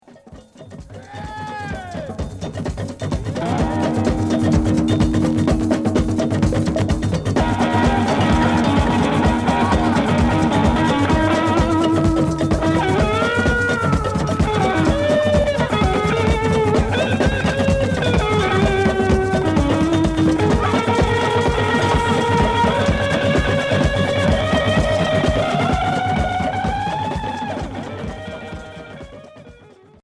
Heavy rock psyché